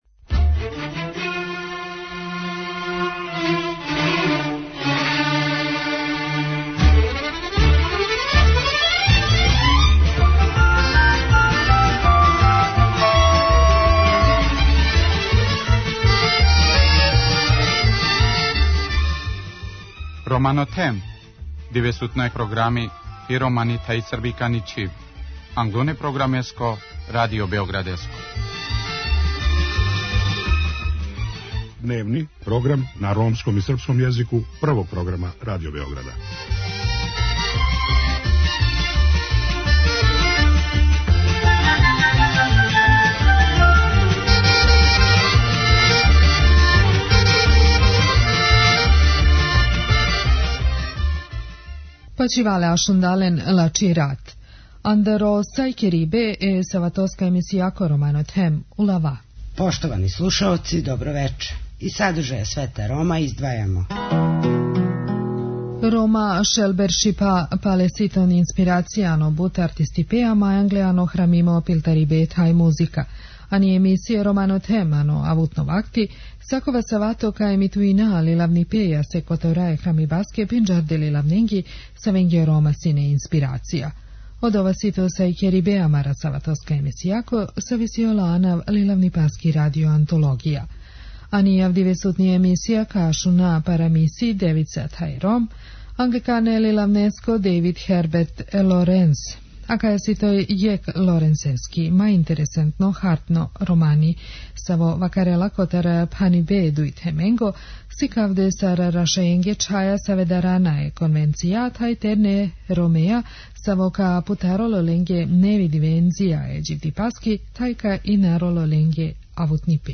У данашњој емисији слушамо приповетку Девица и Циганин енглеског књижевника Дејвида Херберта Лоренса. Ово је један од Лоренсових најузбудљивијих кратких романа који говори о судару два света, оличених у ликовима свештеникове кћери, спутане страхом и конвенцијама, и привлачног младог Рома, који ће јој открити нову димензију живота и преокренути судбину.